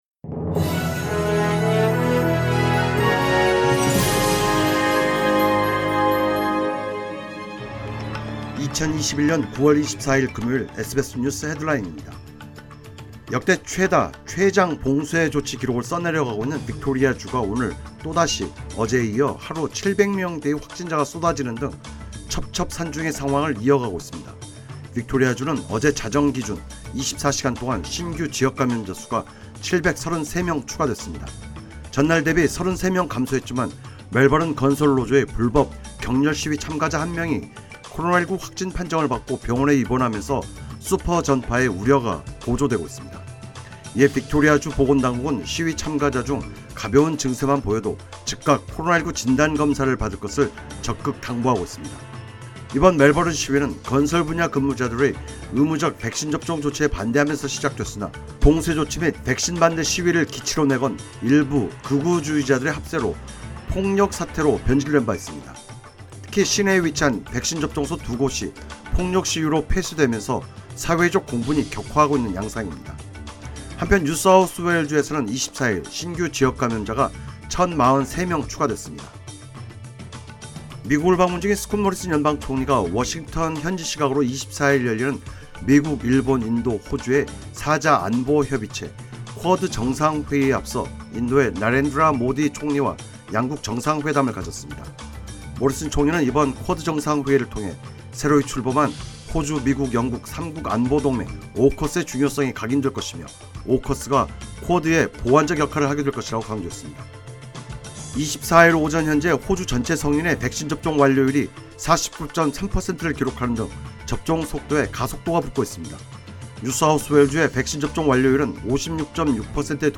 2021년 9월 24일 금요일 SBS 뉴스 헤드라인입니다.